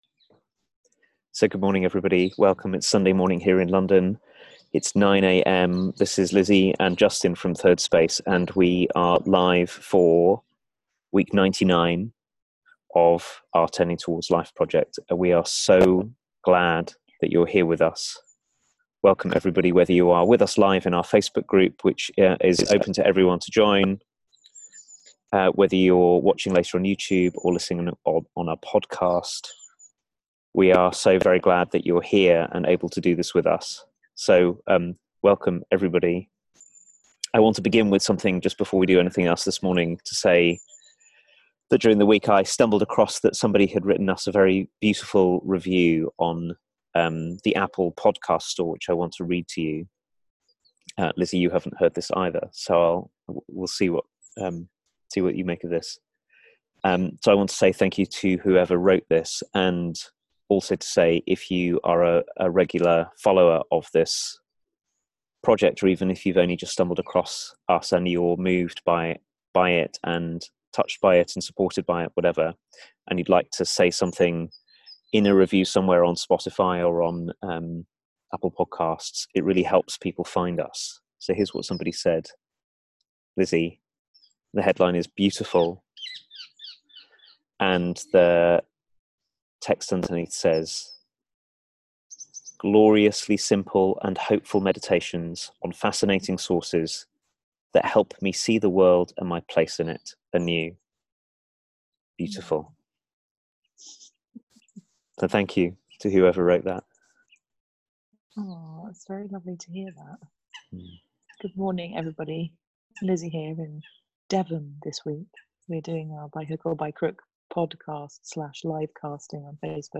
But what if we saw guilt as something to welcome rather than avoid? A conversation about finding a way to be true in our relationships with others
a weekly live 30 minute conversation